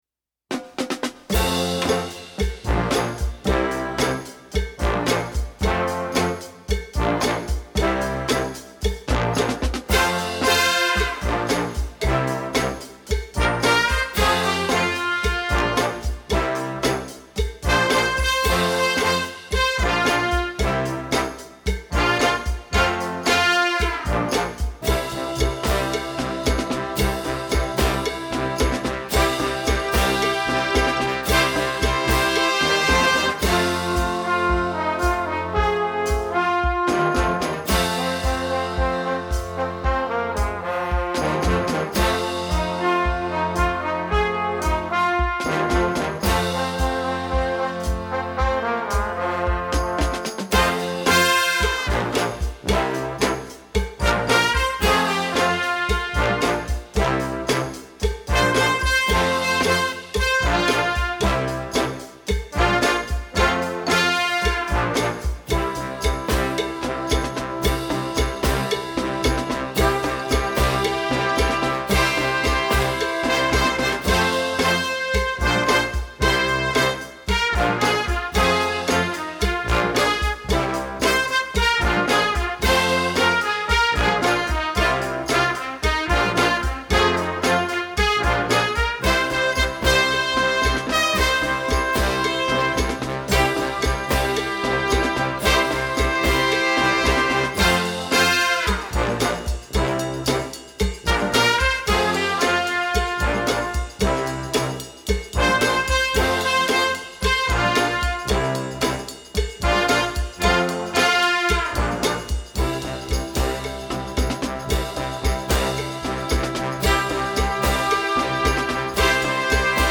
Gattung: Moderner Einzeltitel
Marching-Band
Besetzung: Blasorchester